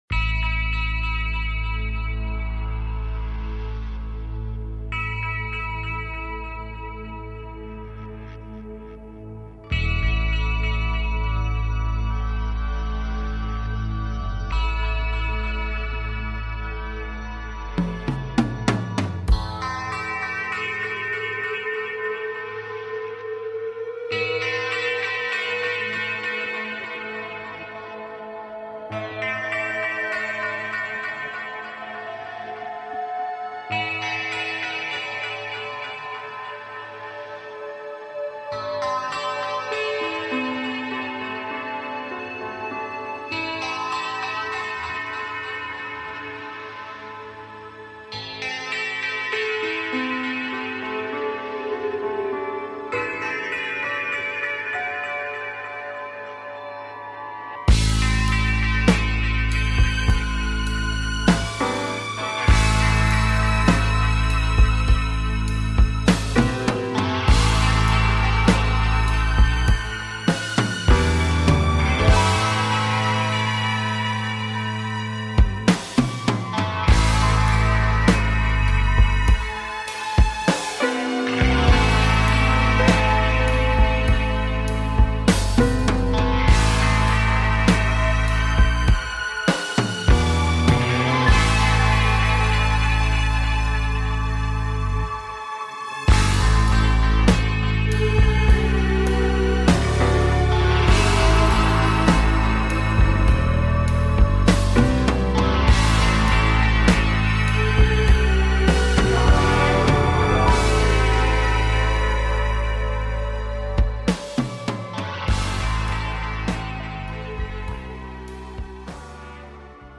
Reviewing listener projects and answering listener calls